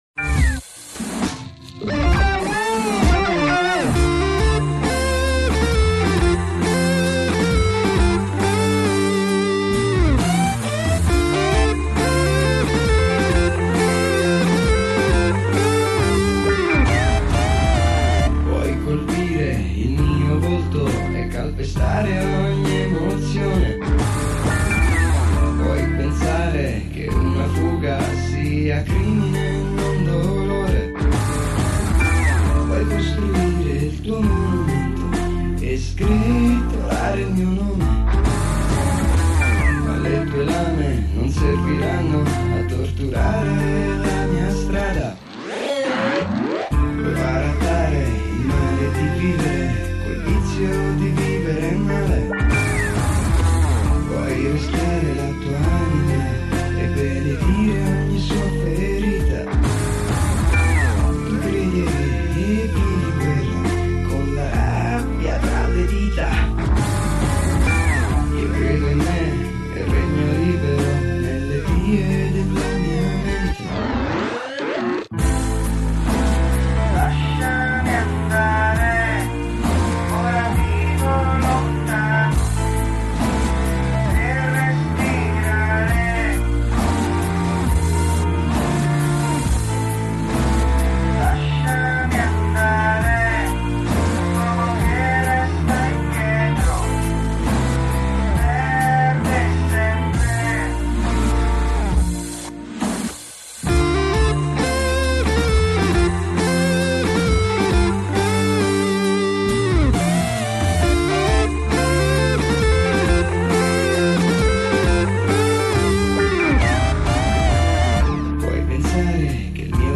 GenerePop